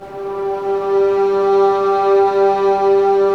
Index of /90_sSampleCDs/Roland L-CD702/VOL-1/STR_Vlns Bow FX/STR_Vls Sordino